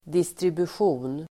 Ladda ner uttalet
Uttal: [distribusj'o:n]